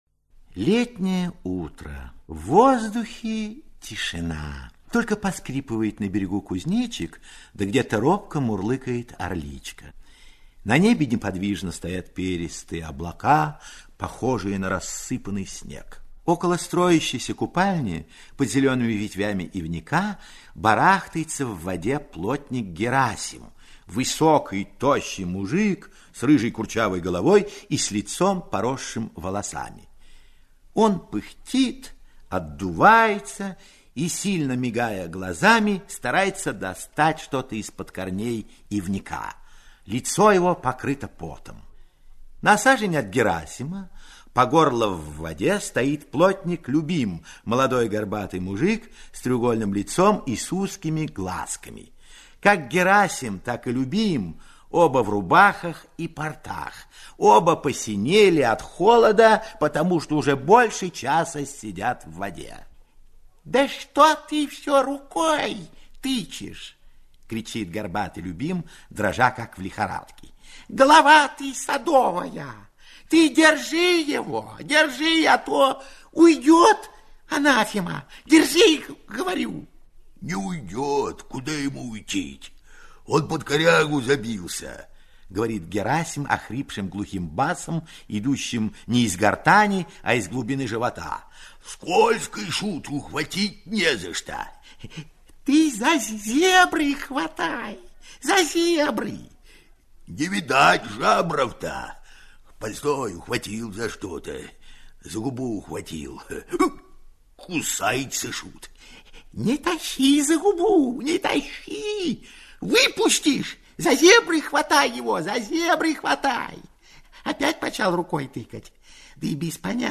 Налим - аудио рассказ Чехова А.П. Рассказ про то, как четверо мужиков и барин весь день вытаскивали крупного налима, забившегося под корягу.